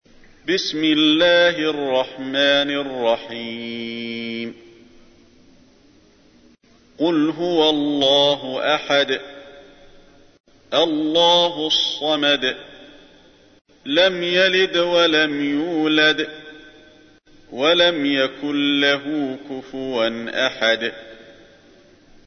تحميل : 112. سورة الإخلاص / القارئ علي الحذيفي / القرآن الكريم / موقع يا حسين